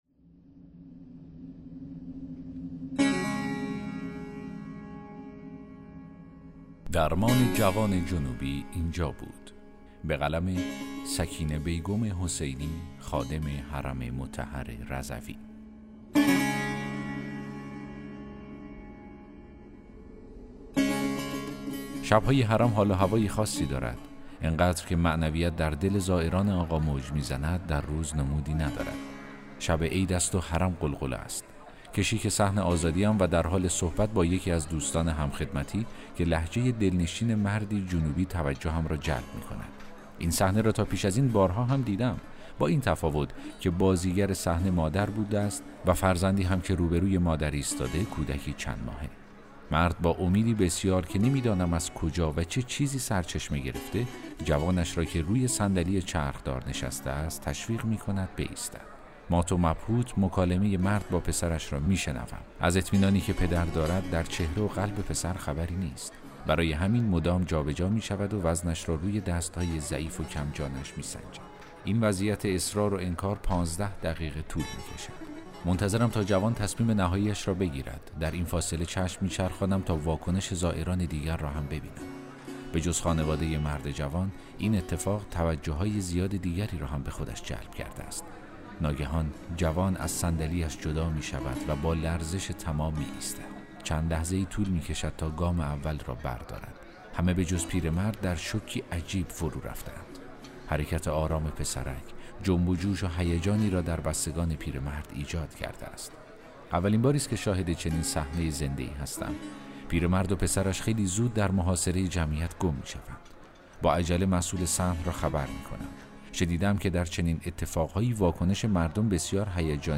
داستان صوتی: درمان جوان جنوبی اینجا بود